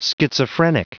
Prononciation du mot schizophrenic en anglais (fichier audio)
Prononciation du mot : schizophrenic